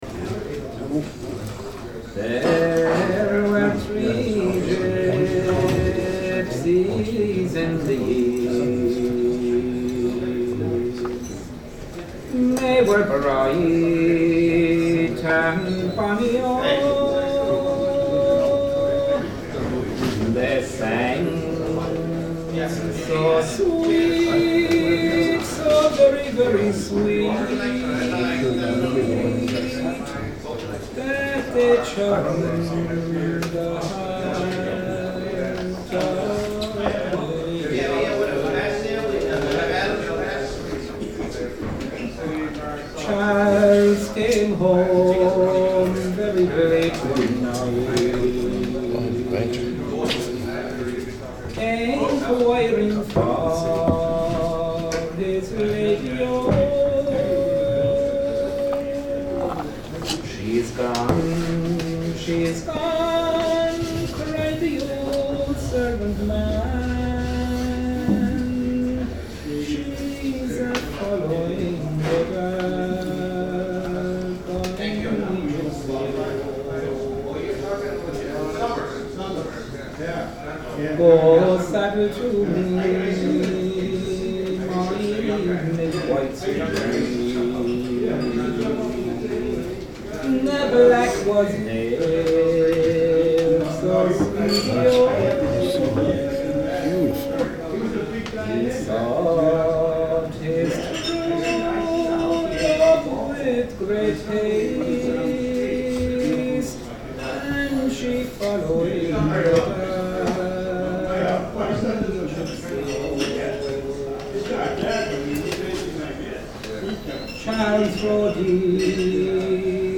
Great sesh at the Green Briar on Monday!
Guitar
Fiddle
The whole crowd.